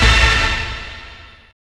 hitTTE68016stabhit-A.wav